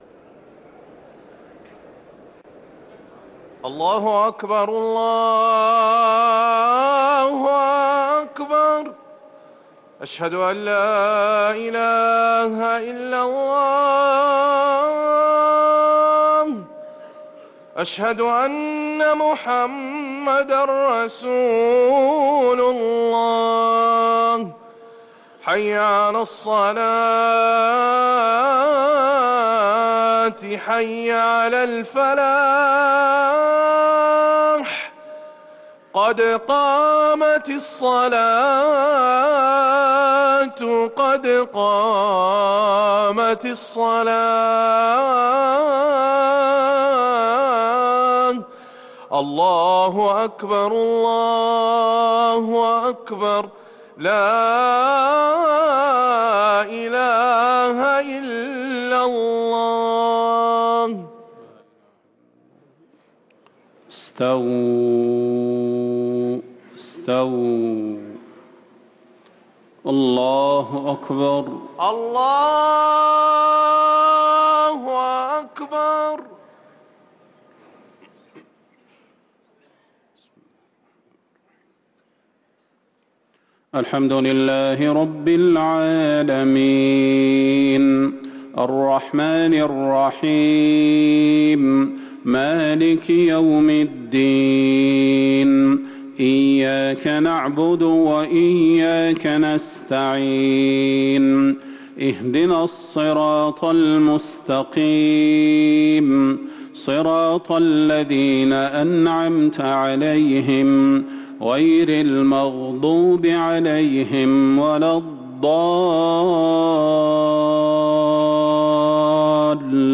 صلاة المغرب للقارئ صلاح البدير 22 ربيع الآخر 1445 هـ
تِلَاوَات الْحَرَمَيْن .